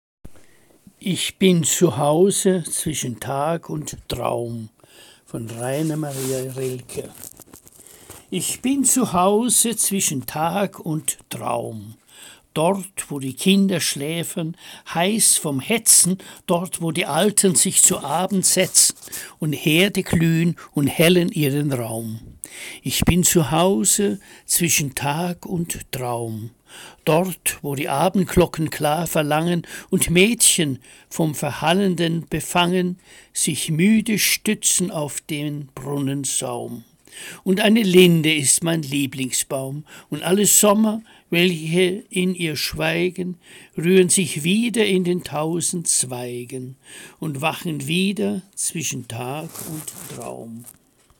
Lesung - Musikvideo